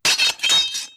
Melee Weapon Attack 1.wav